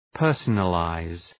personalize.mp3